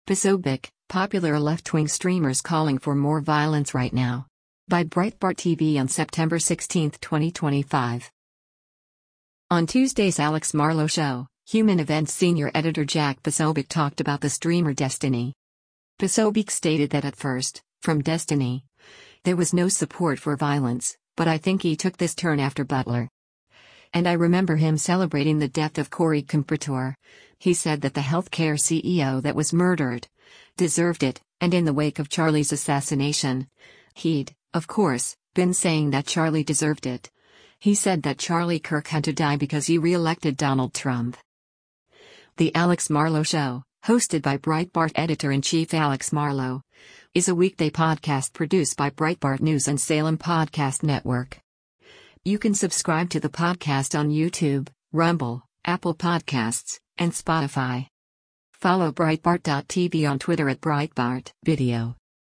is a weekday podcast produced by Breitbart News and Salem Podcast Network.